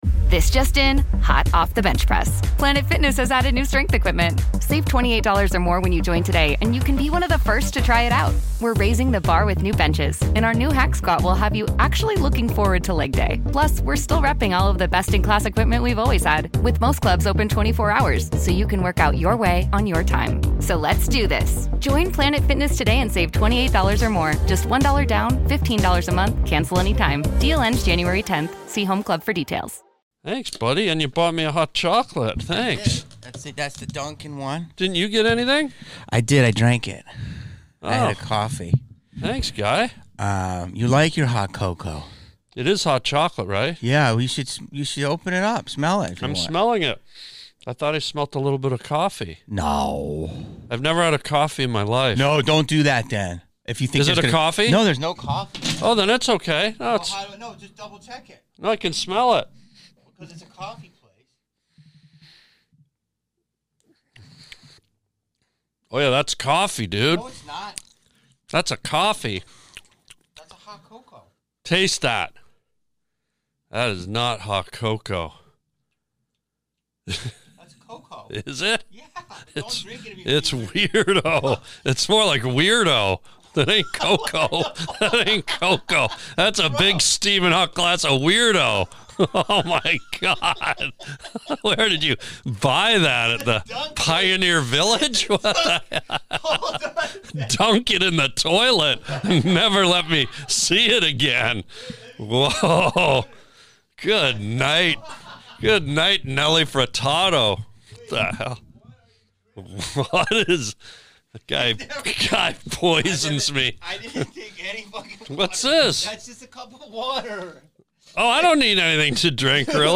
HATE TO BREAK IT TO YA is a weekly podcast hosted by actor/comedian Jamie Kennedy. Jamie is on a constant quest to get to the bottom of things.